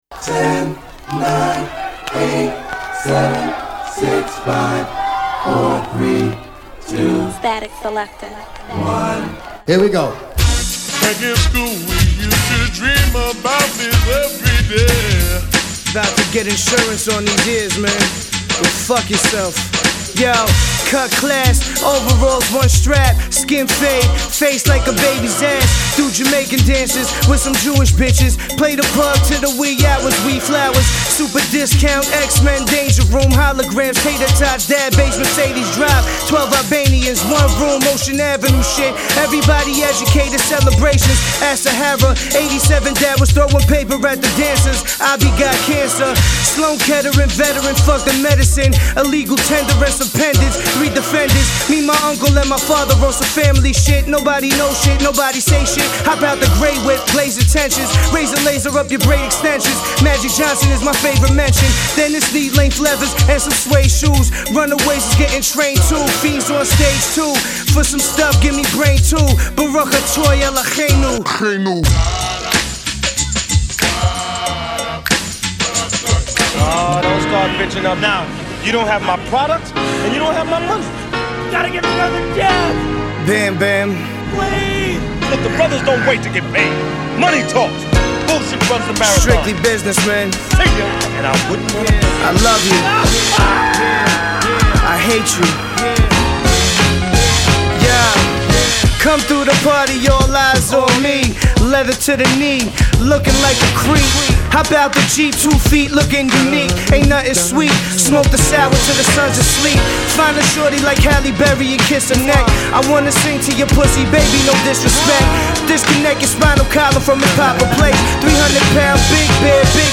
chopped and screwed sample